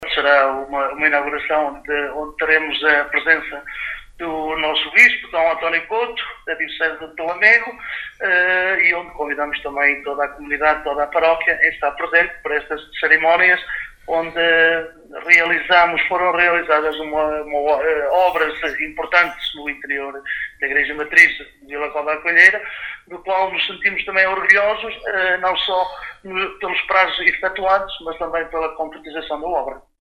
Fernando Guedes, Presidente da Junta de Freguesia de Vila Cova à Coelheira, deixa igualmente, o convite a toda a comunidade.